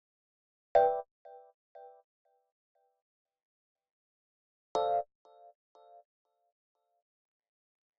29 ElPiano PT1.wav